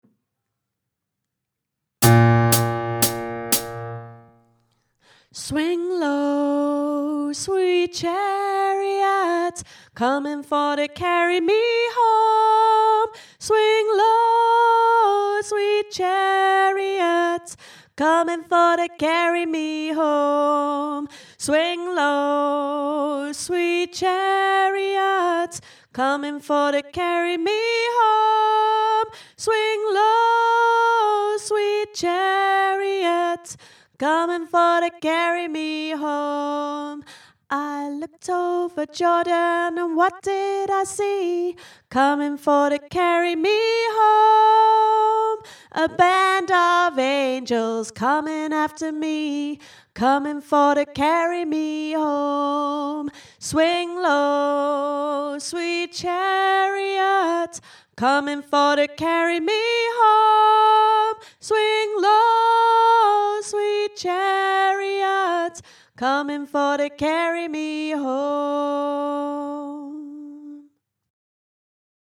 swing-down-swing-low-top-harmony.mp3